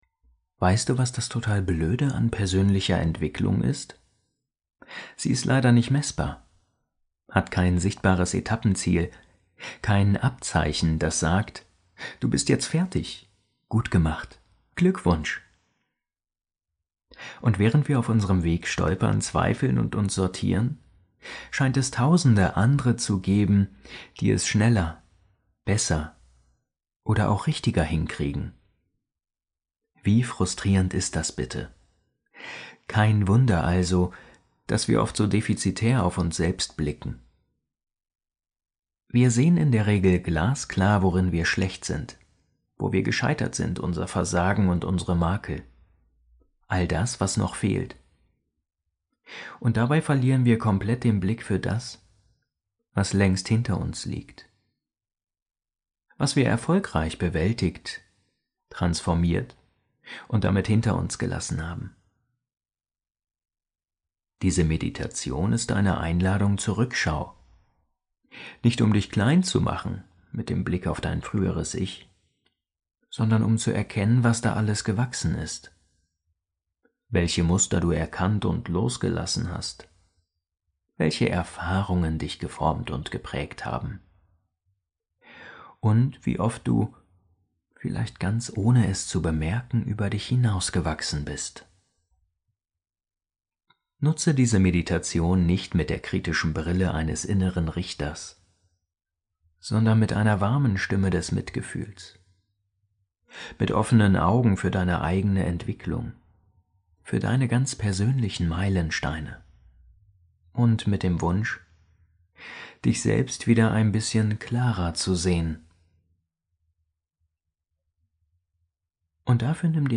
Diese geführte Meditation lädt dich zu einer liebevollen Rückschau auf deine persönliche Entwicklung ein.